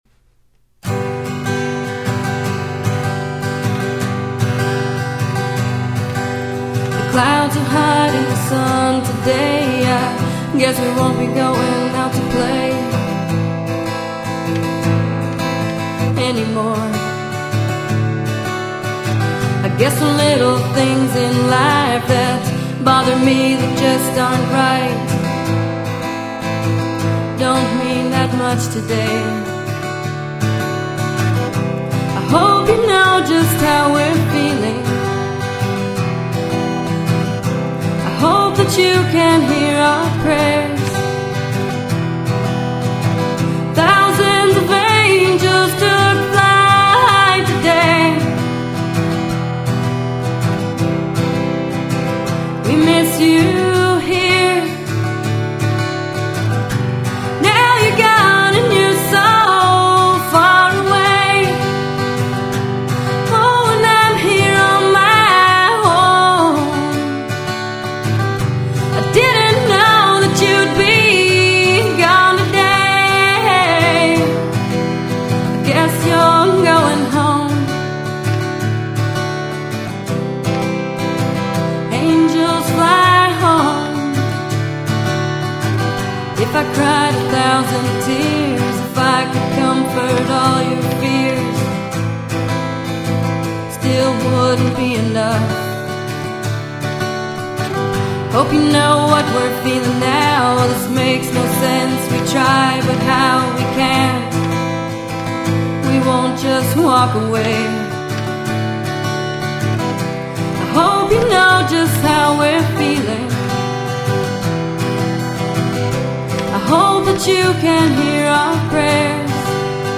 But the music is also stunning.